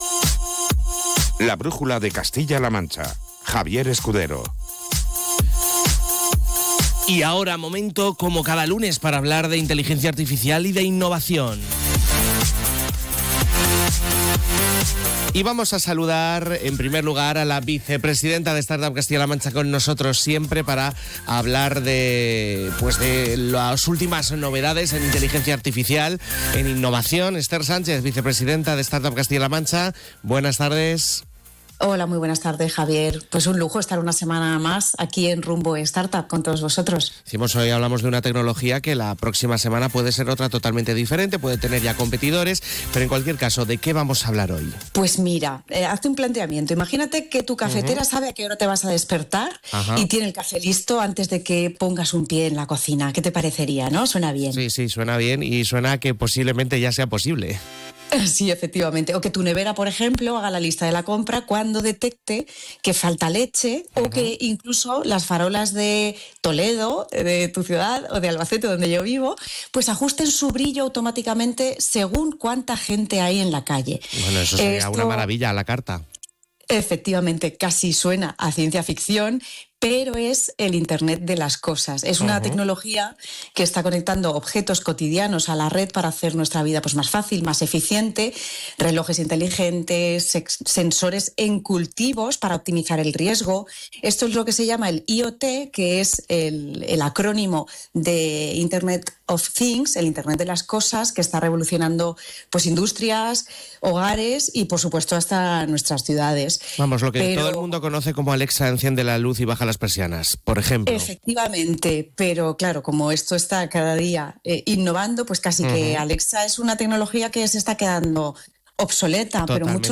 No te pierdas esta charla reveladora sobre un futuro cada vez más conectado.